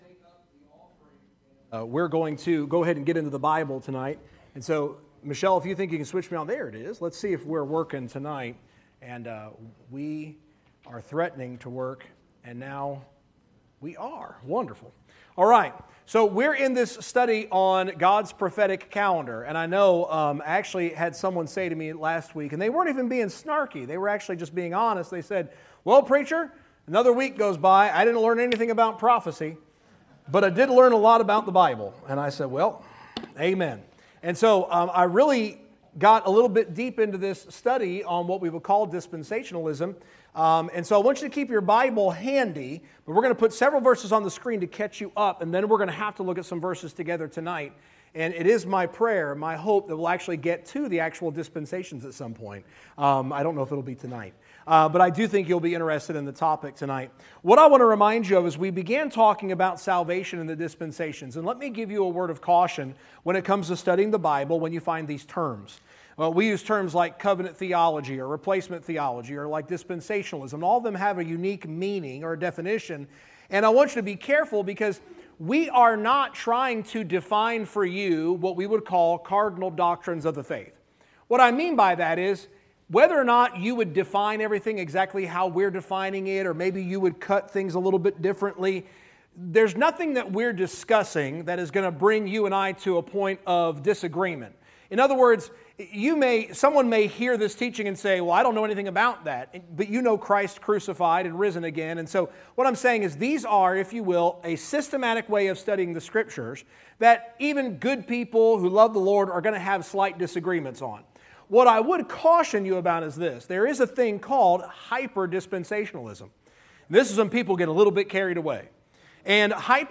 Sermons | Victory Hill Baptist Church